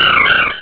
Cri de Jirachi dans Pokémon Rubis et Saphir.